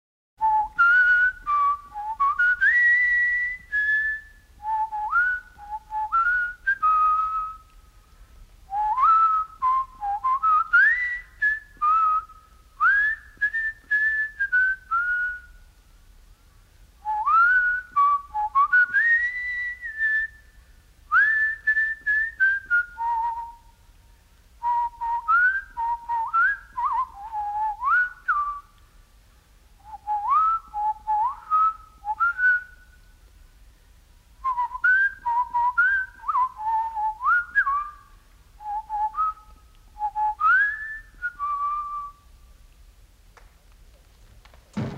A kind of carnival waltz
in the best-possible monaural sound.
Whistling Version